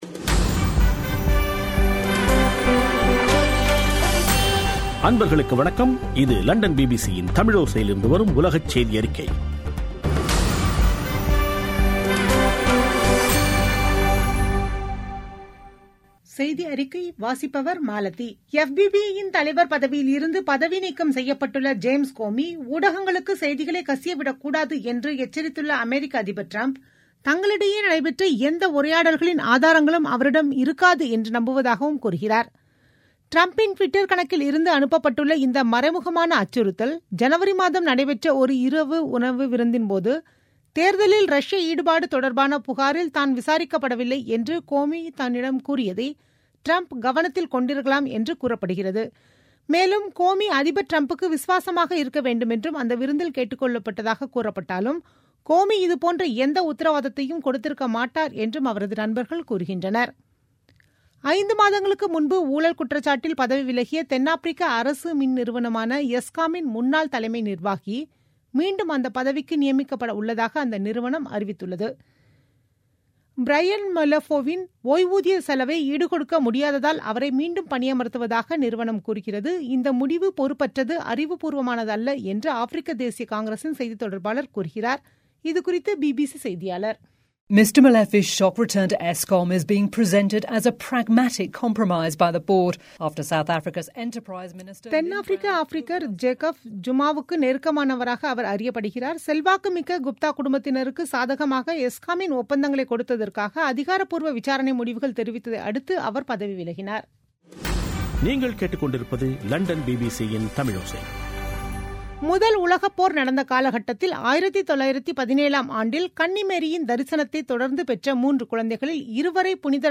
பிபிசி தமிழோசை செய்தியறிக்கை (12/05/2017)